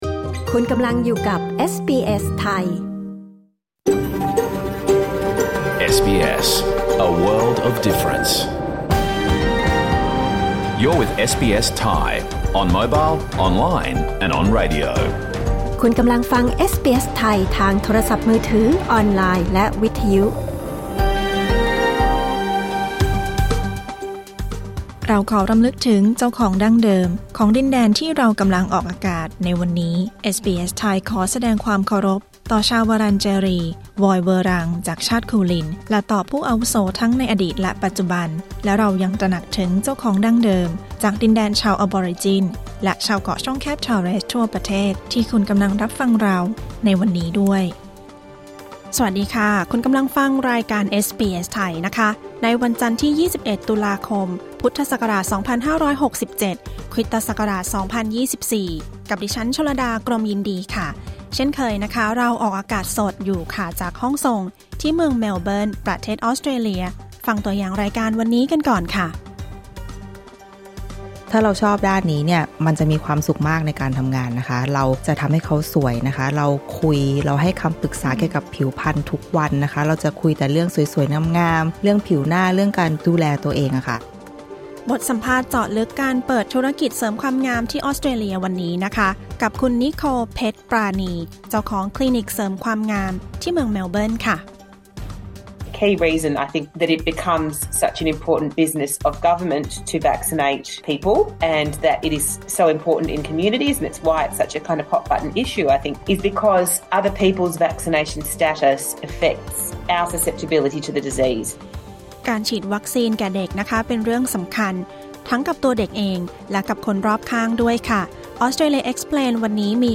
รายการสด 21 ตุลาคม 2567